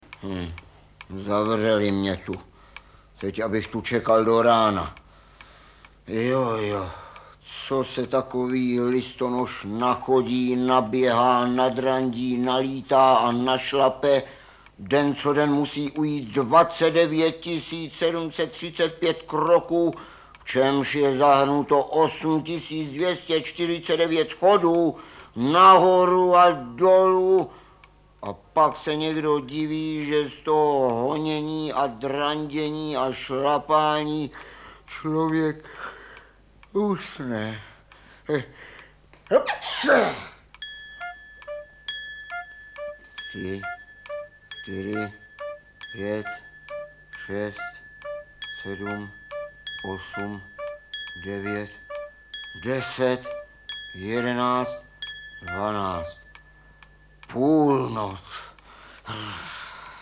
Audiobook
Read: Stanislav Neumann